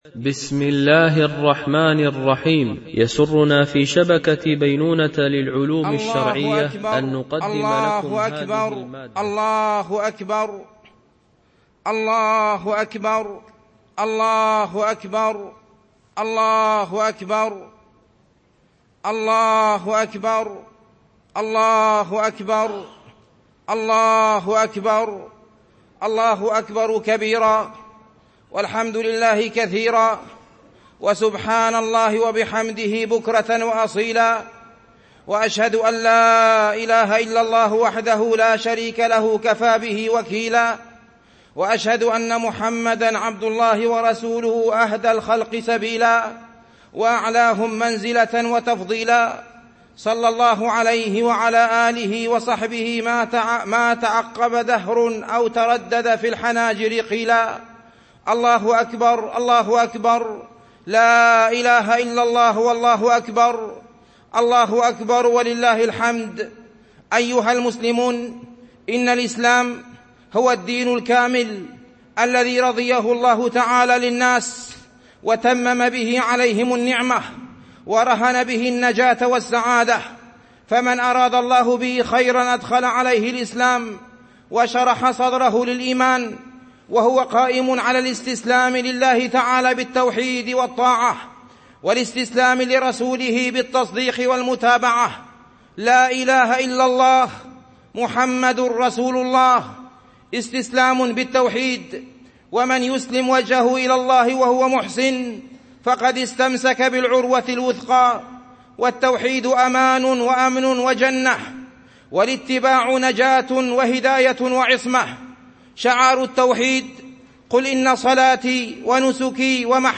خطبة عيد الفطر 1439 هـ